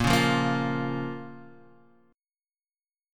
A#m chord